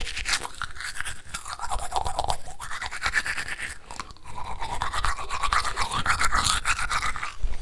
Cepillándose los dientes
Grabación sonora del sonido producido por una persona al cepillarse los dientes. Se aprecia el sonido del cepillo frotando contra los dientes en la cabidad bucal
Sonidos: Acciones humanas